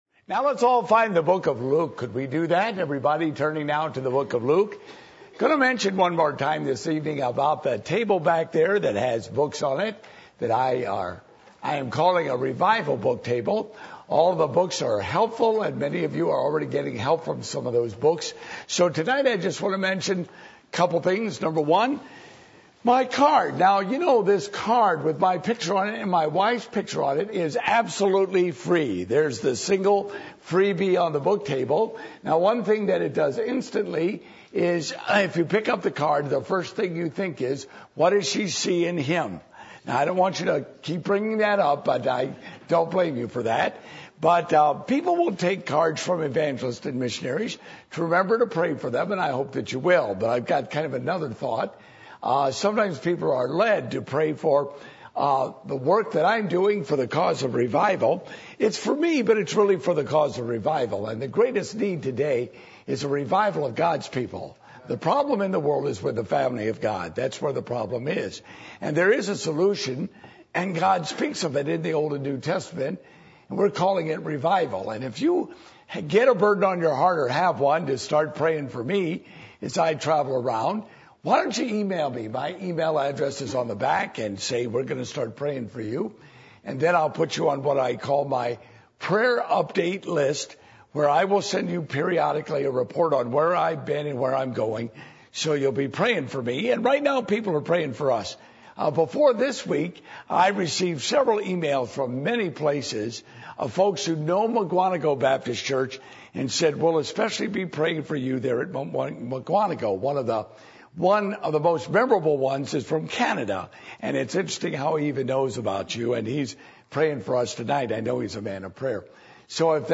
Passage: Luke 19:1-10 Service Type: Revival Meetings